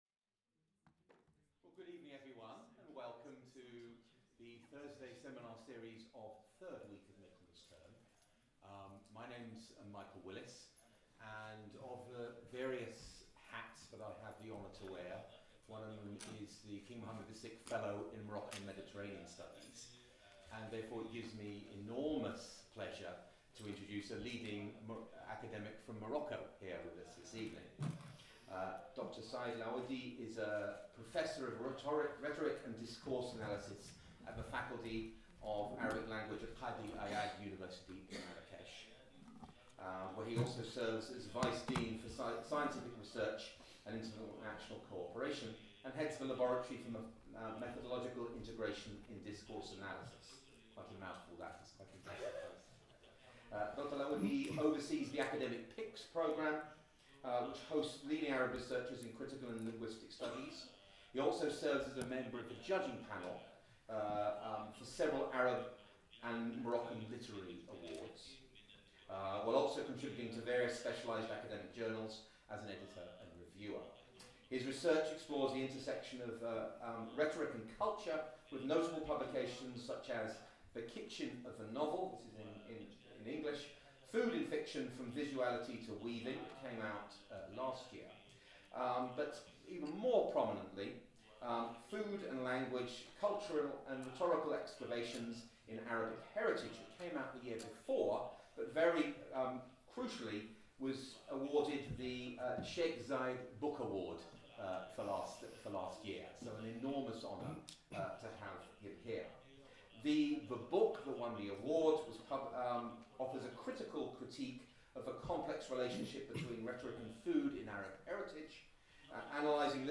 Seminar in English and Arabic.